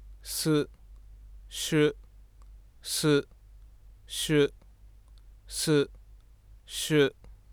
ここには「ス」と「シュ」の発音が含まれています。
各発話の前半部分が摩擦に相当する部分です。
つまり，「ス」の子音のほうが高い周波数域にエネルギーが分布しがちであるということです。
su_shu.wav